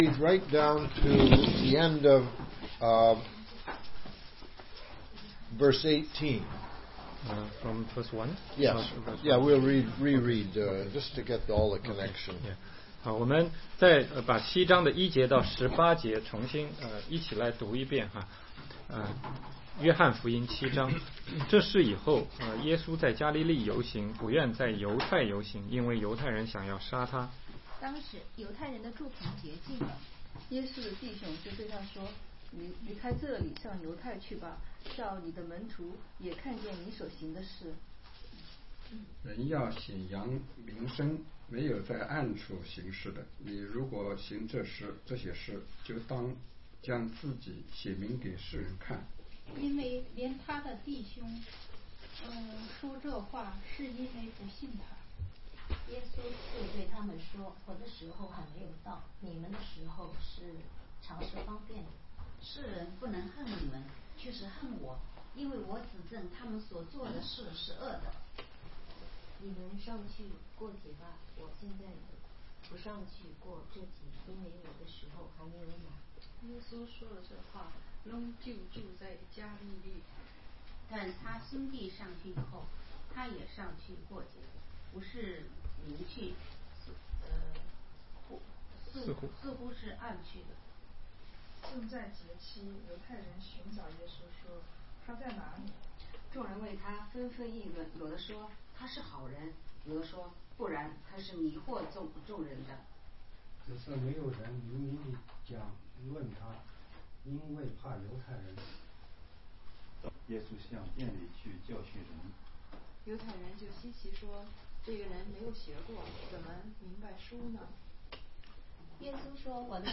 16街讲道录音 - 约翰福音7章1-5节续